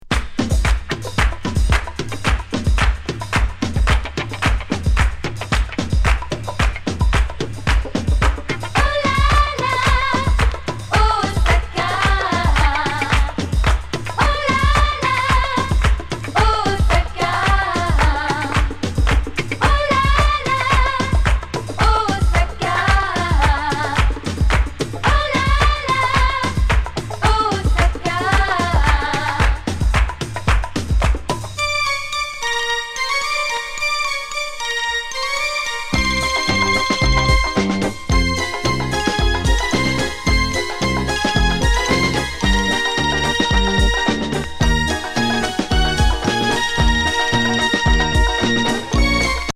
クラッピン&コーラス・ファニー・ディスコ!